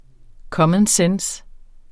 Udtale [ ˈkʌmən ˈsεns ]